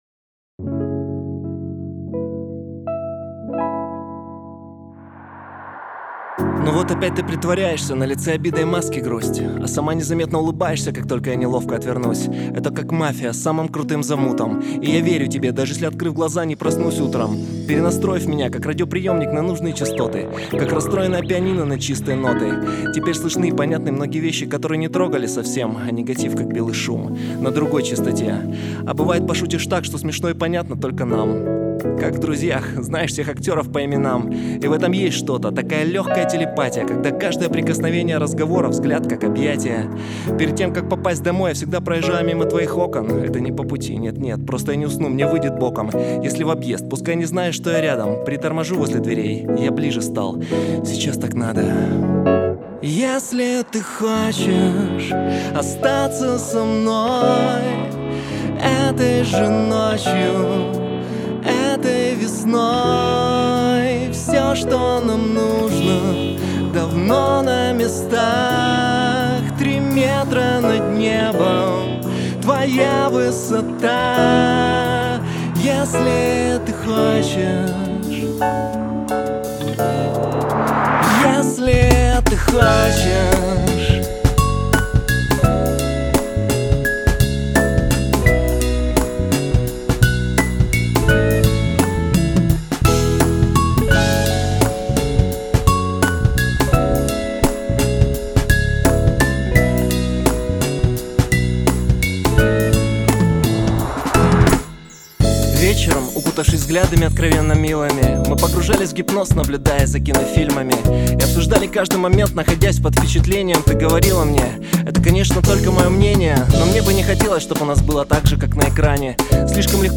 джазок
Скинул мне товарищ треки, напел я голос, когда начал сводить, обнаружил, что уровень громкости RMS в начале песни -15, затем когда вступают барабаны -10, а в кульминации доходит до -6, при этом пики конкретные на транспорте Кубейза, но искажений нет.